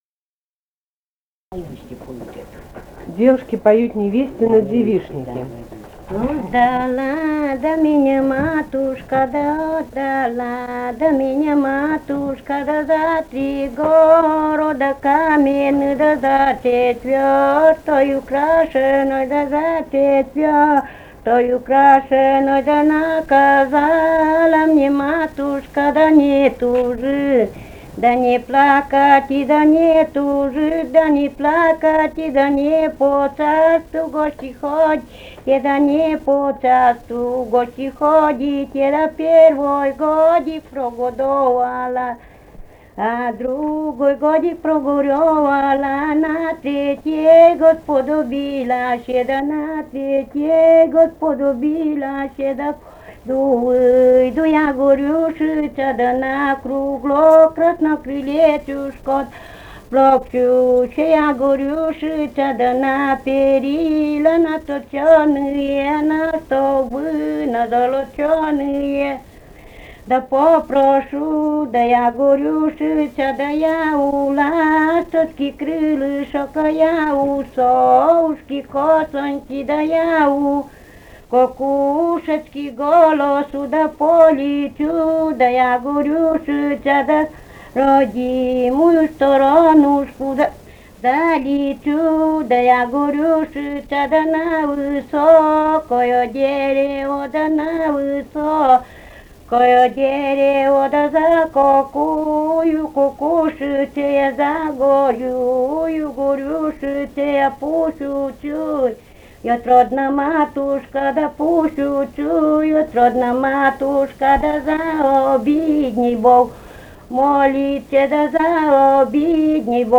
«Отдала да меня матушка» (свадебная на девишнике).